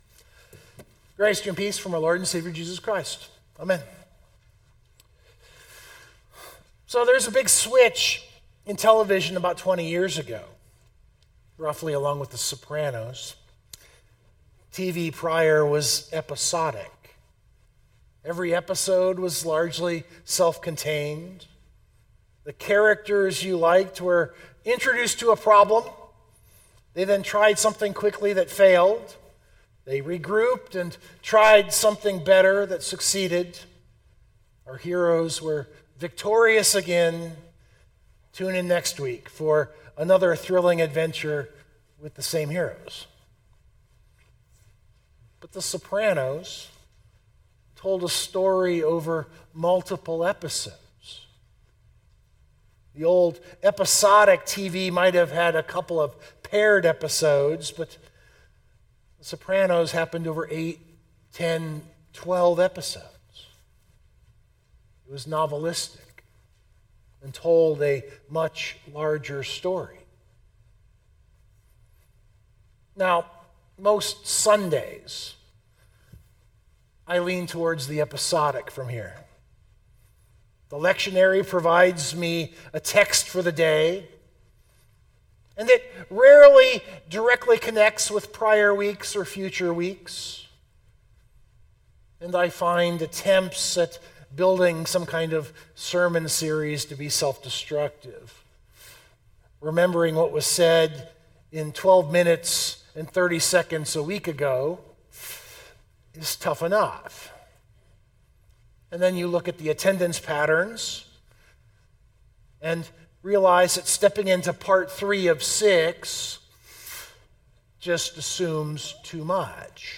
022526 MW1 SermonDownload Biblical Text: Psalm 50:1-15 I rarely do this.